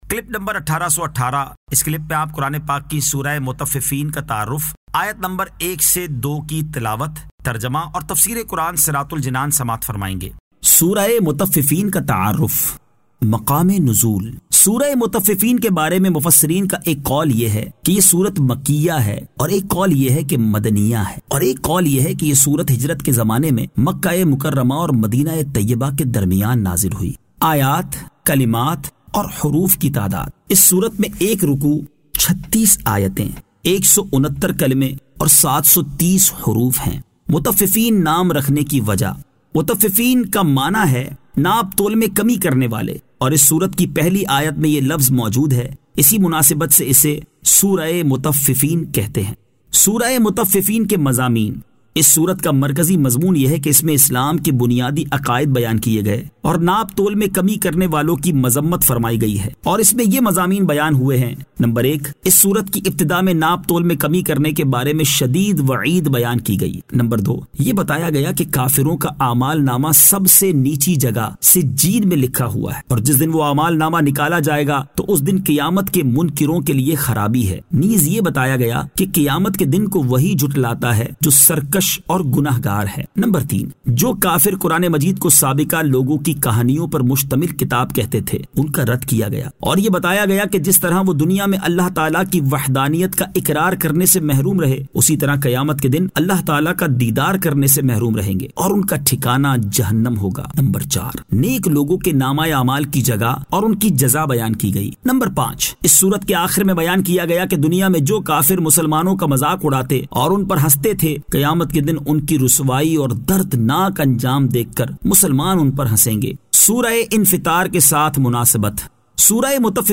Surah Al-Mutaffifeen 01 To 02 Tilawat , Tarjama , Tafseer